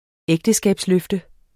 Udtale [ ˈεgdəsgabs- ]